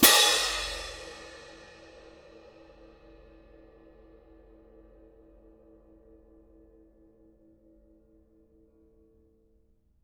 cymbal-crash1_ff_rr1.wav